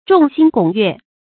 眾星拱月 注音： ㄓㄨㄙˋ ㄒㄧㄥ ㄍㄨㄙˇ ㄩㄝˋ 讀音讀法： 意思解釋： 許多星星聚集、環繞著月亮。